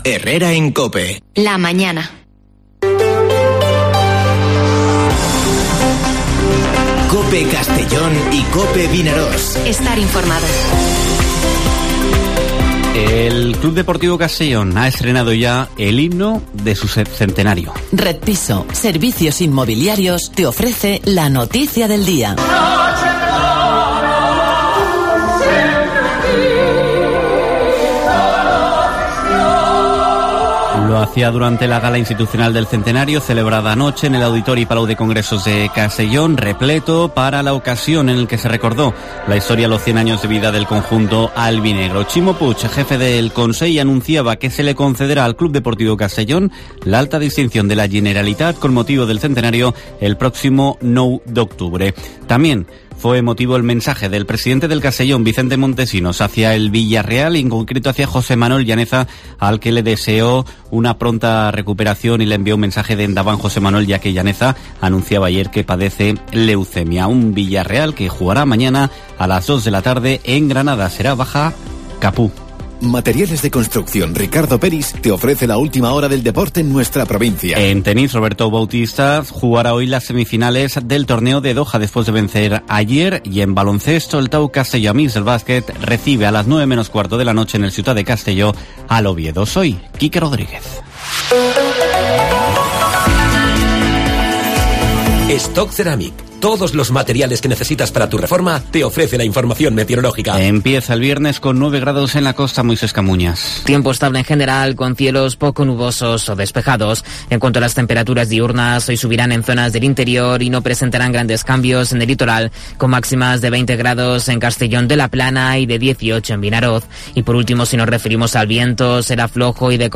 Informativo Herrera en COPE en la provincia de Castellón (18/02/2022)